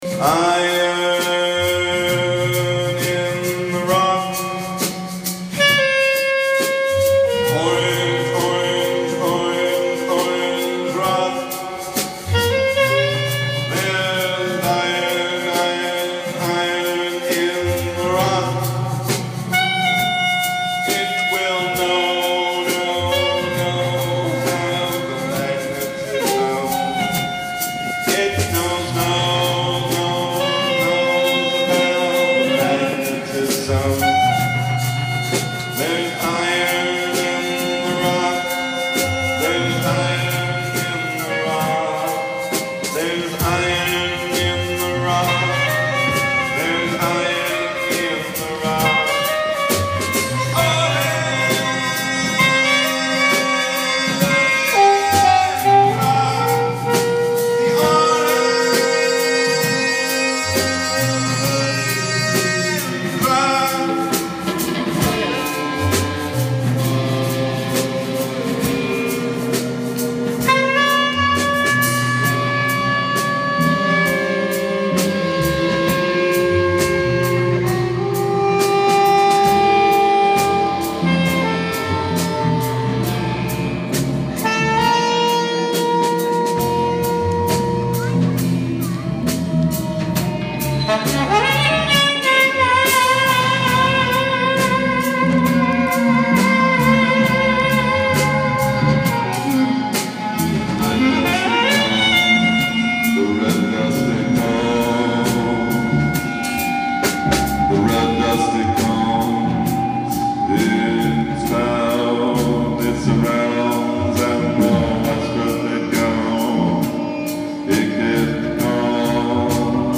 sax
drums
sitar/voice
guitar/voice
dobro bass loop
ALL MUSIC IS IMPROVISED ON SITE